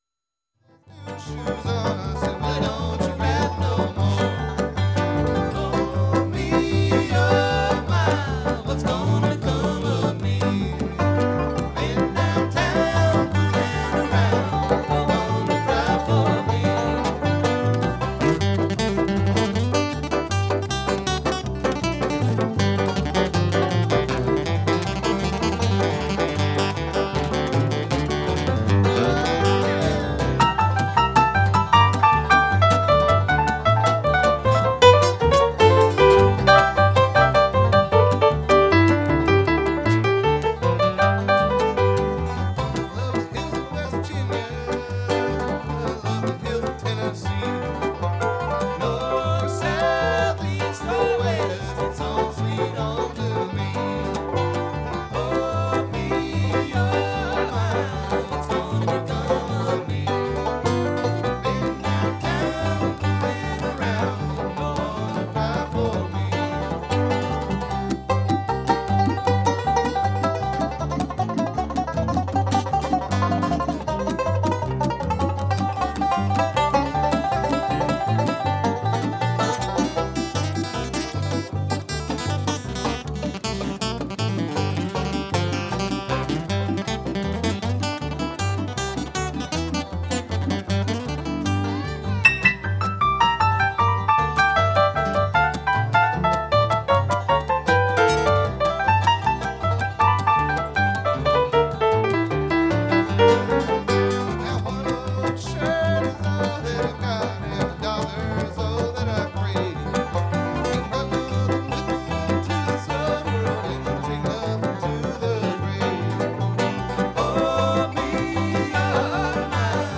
A laid-back mix of bluegrass classics
Banjo,Fiddle,Mandolin,Electric Banjo, Vocals
Organ, Clavinet, Piano, Bass, Melodica, Vocals
Guitar,Mandolin,Vocals